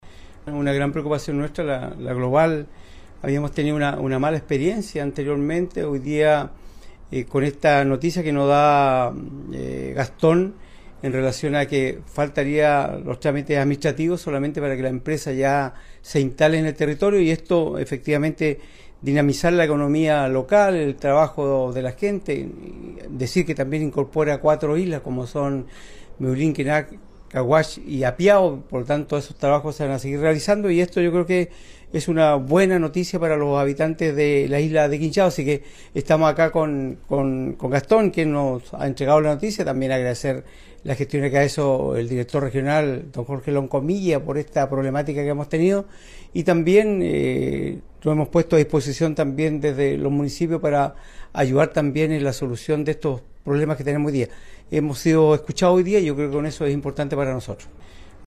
Celebró este regreso de una empresa a cargo, el alcalde de la comuna de Quinchao, René Garcés, dando cuenta que es una buena noticia el regreso de la Global de Conservación de caminos.